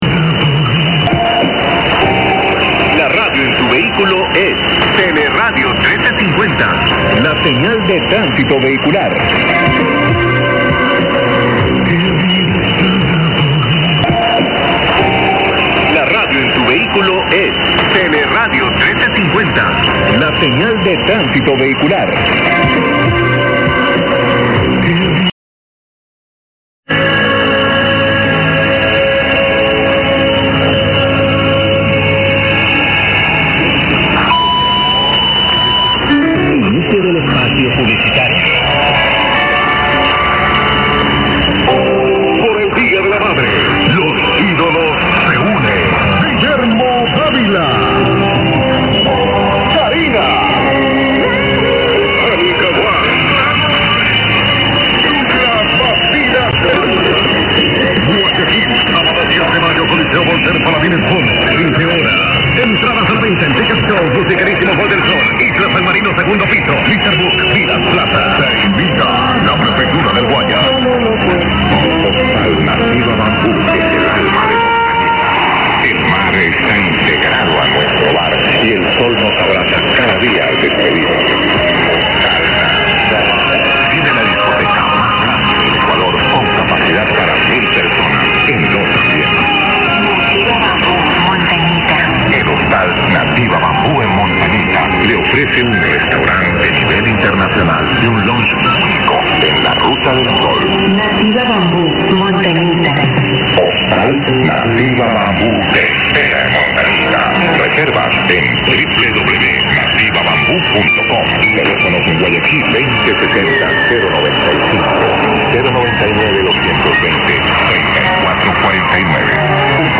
0404 loud signal and ss ads, poss id at end (and same clip twice at start) 2/5